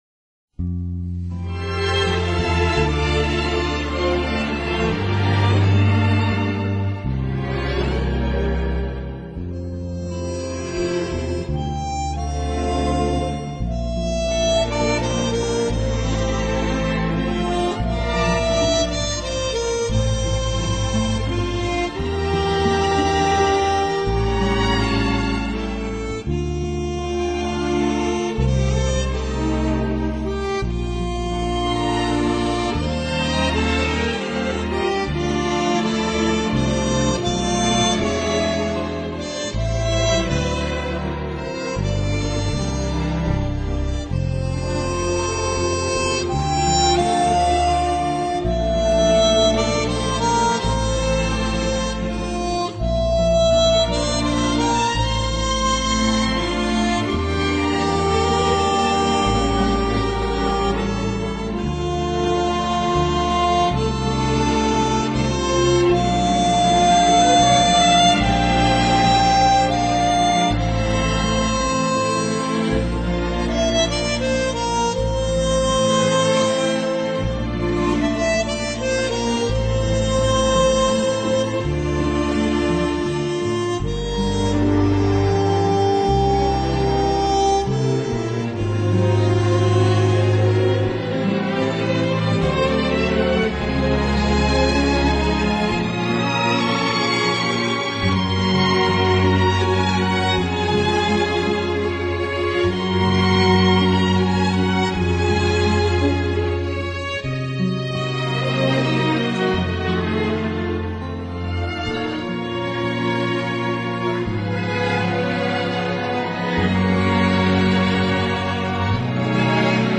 音乐类型：SoundTrack 原声
音乐风格：Soundtrack,New Age
最富气质的改编配乐、最抒情的口琴演奏。